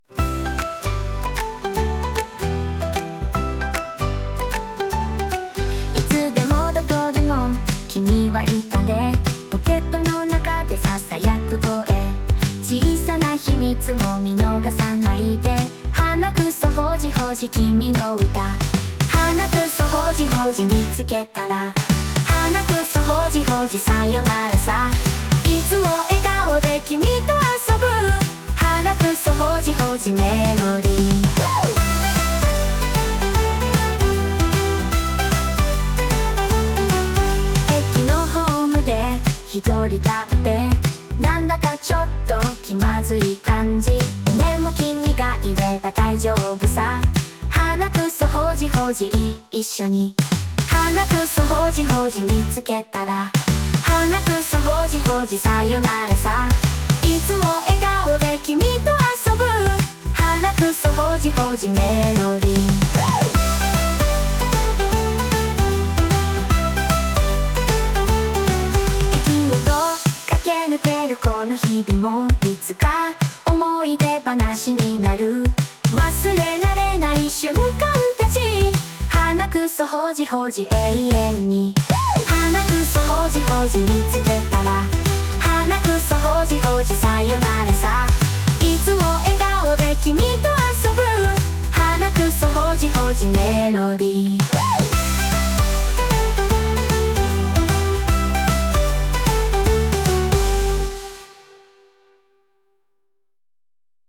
playful pop